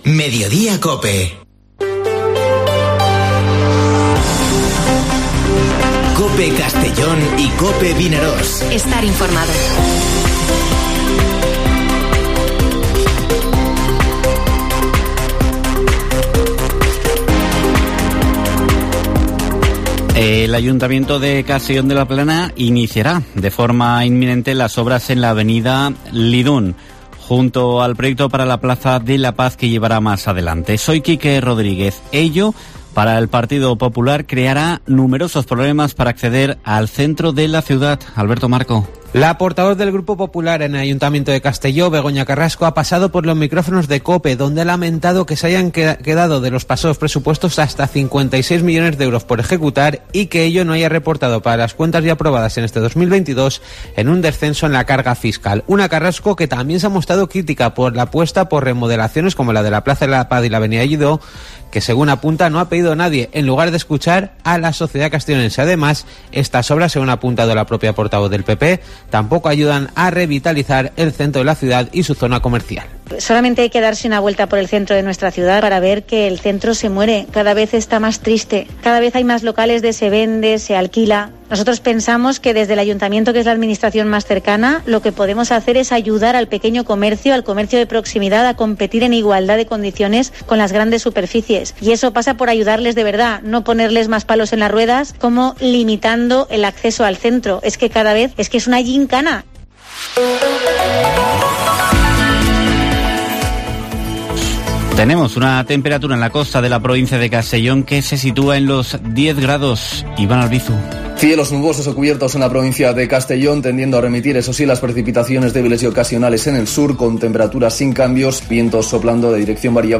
Informativo Mediodía COPE en la provincia de Castellón (24/01/2022)